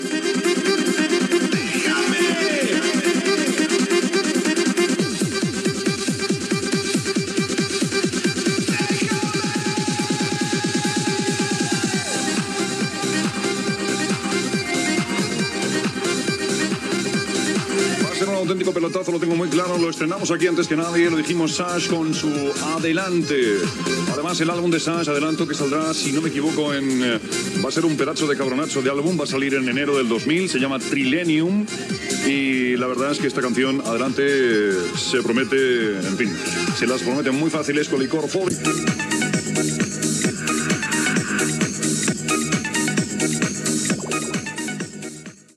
Presentació d'un tema musical
Musical